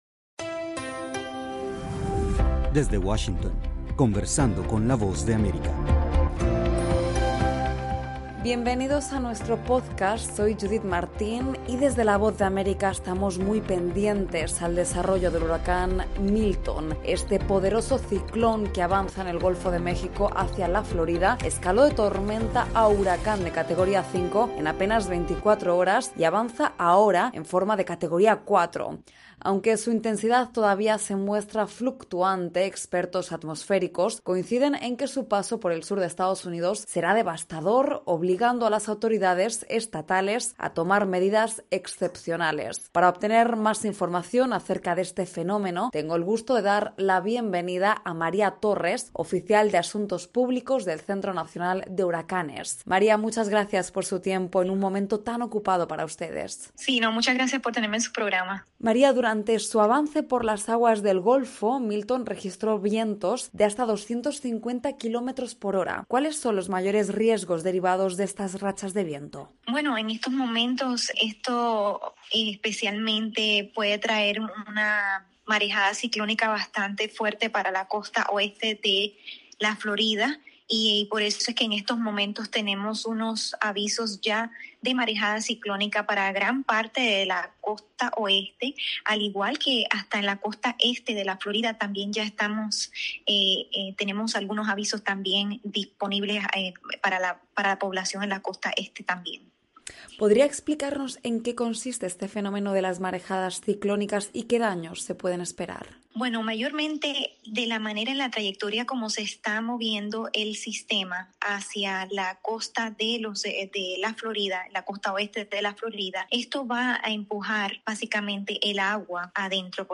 Florida aguarda la llegada del huracán Milton con evacuaciones masivas que afectan a millones de residentes, los meteorólogos anticipan daños y devastación. Para comprender la magnitud de este sistema, conversamos con